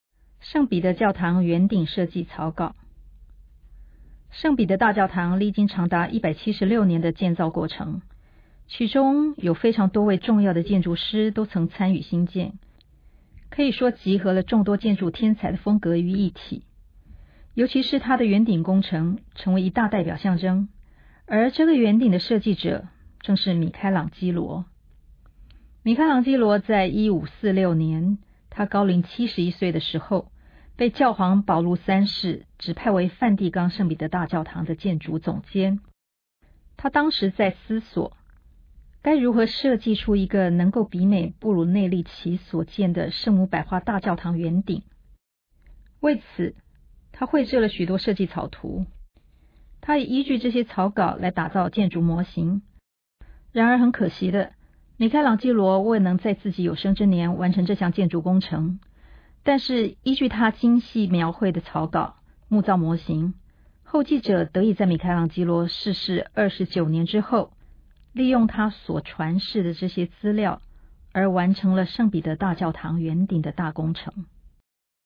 語音導覽 000:00:00 下載